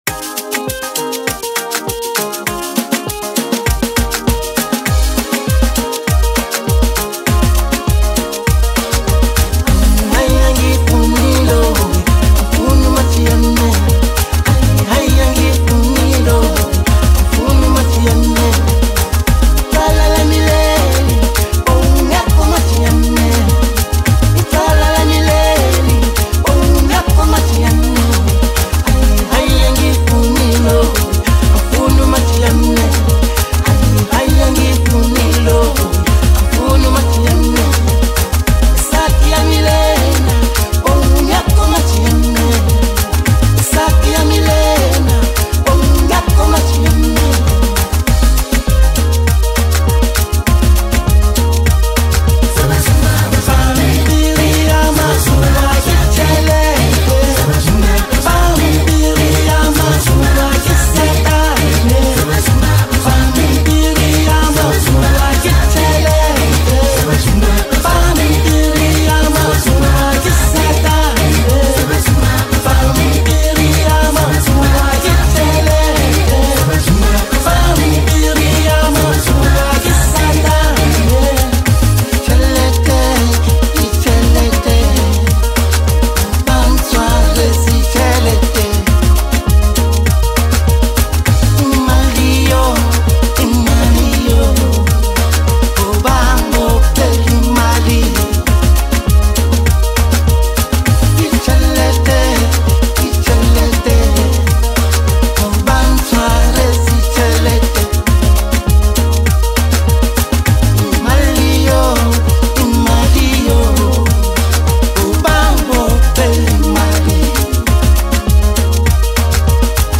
Home » Gqom » Hip Hop » Kwaito » Latest Mix
blending sharp lyrical delivery, mesmerizing instrumentals